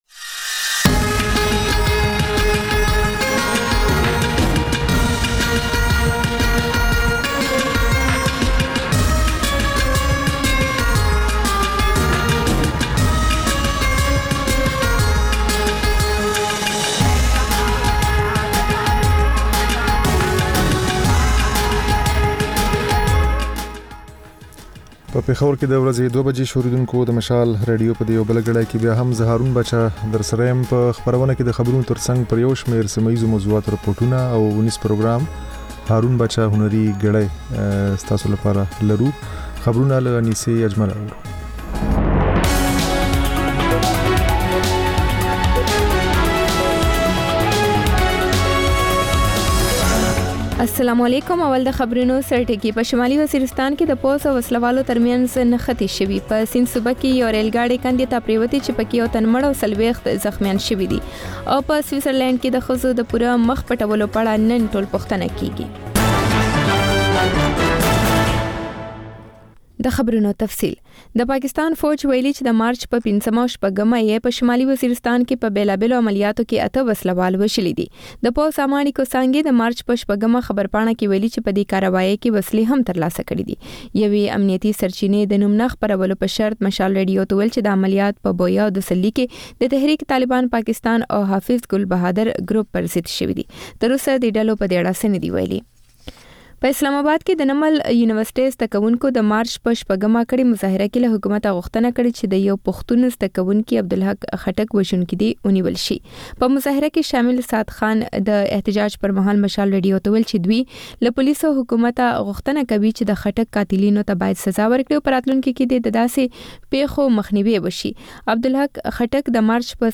د مشال راډیو دویمه ماسپښینۍ خپرونه. په دې خپرونه کې لومړی خبرونه او بیا ځانګړې خپرونه خپرېږي.